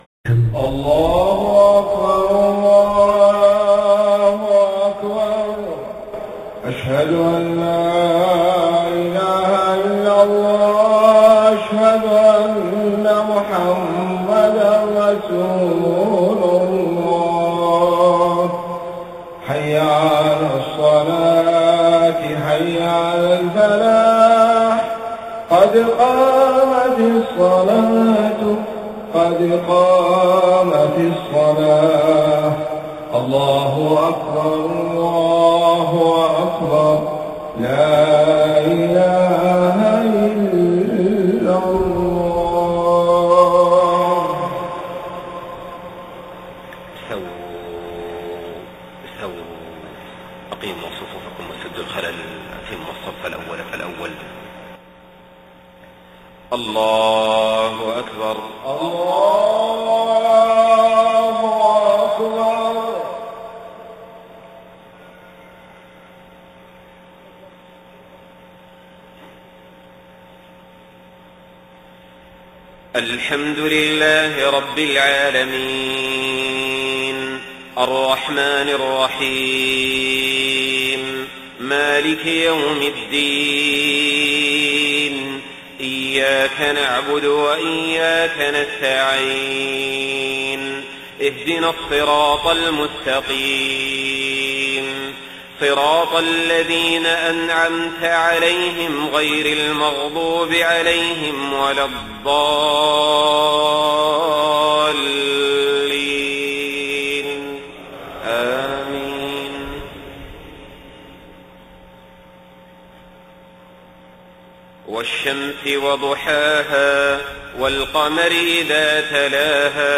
صلاة العشاء 4 صفر 1430هـ سورتي الشمس والتين > 1430 🕋 > الفروض - تلاوات الحرمين